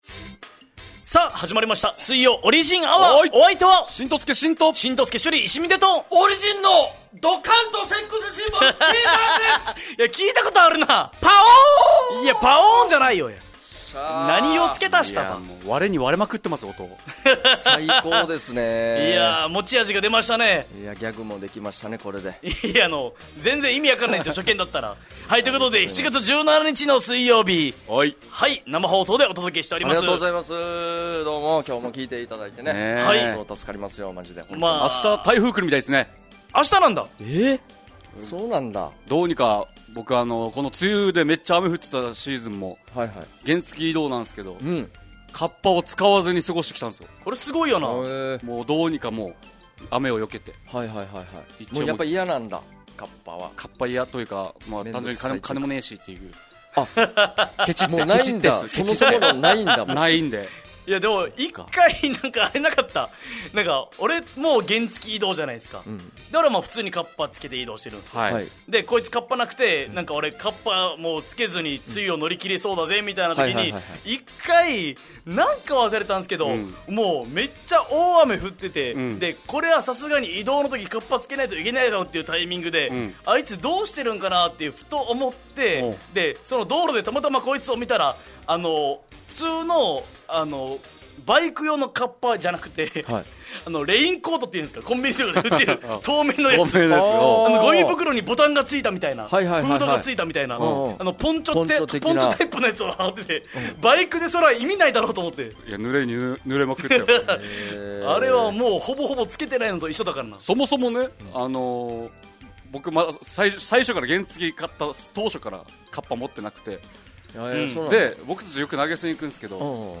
fm那覇がお届けする沖縄のお笑い集団・オリジンメンバー出演のバラエティ番組じゅん選